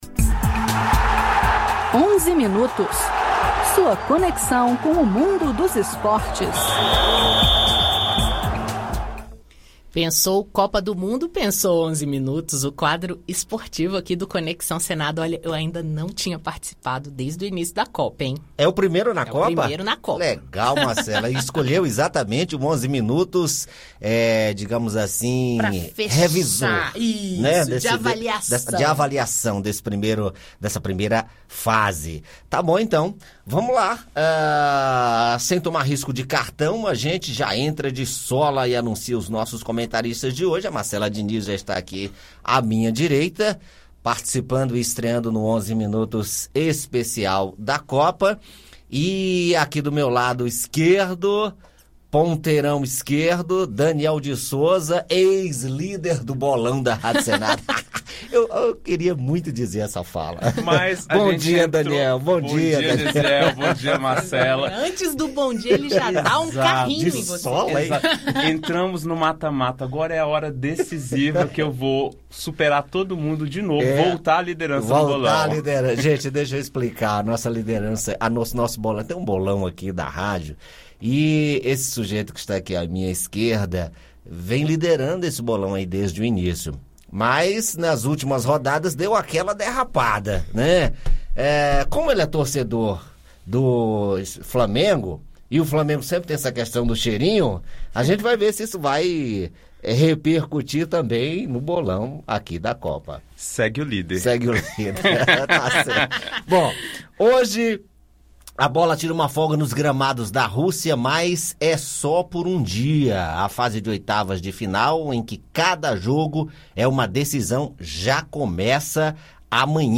Ouça o áudio com os comentários da equipe da Rádio senado, sobre a Copa da Rússia.